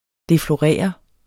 Udtale [ defloˈʁεˀʌ ]